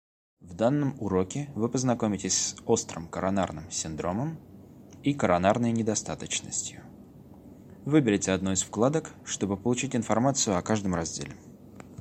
Перевод и озвучка на русском (медицина)